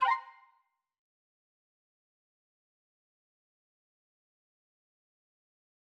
confirm_style_4_001.wav